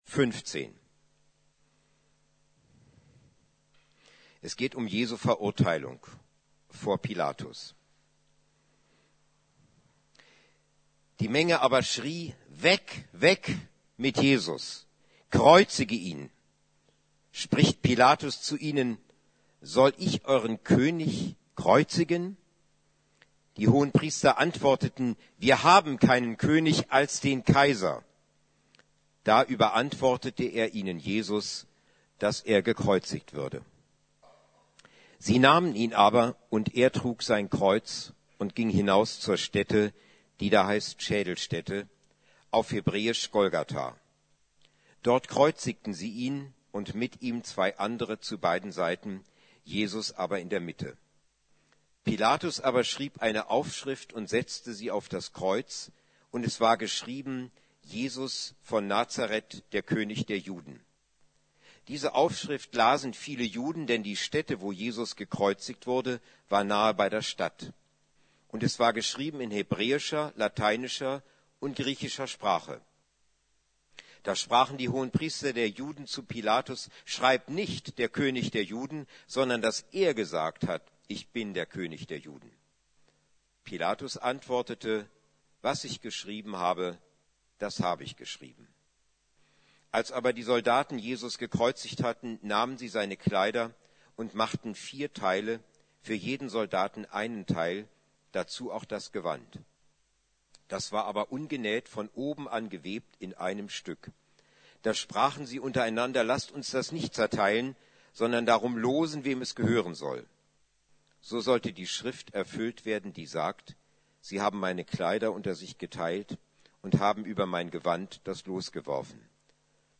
Karfreitag 2016 ~ Predigten der LUKAS GEMEINDE Podcast